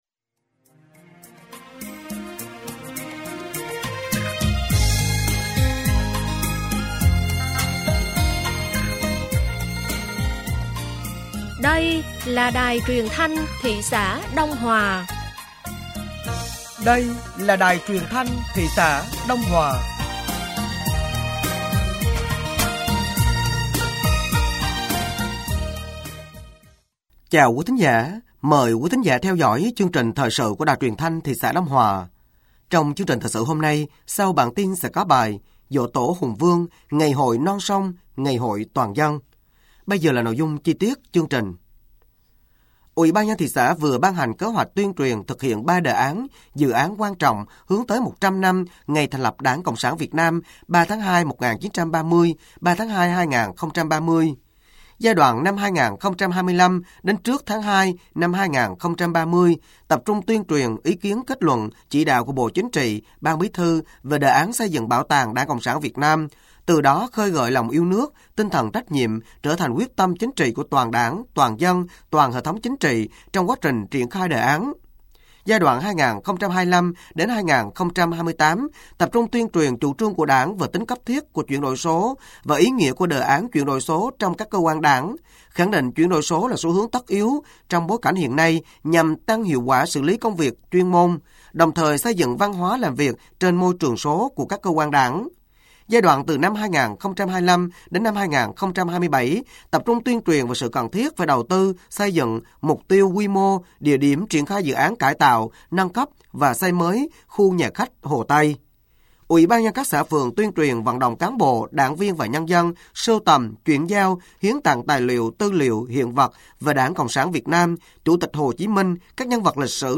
Thời sự tối ngày 07 và sáng ngày 08 tháng 4 năm 2025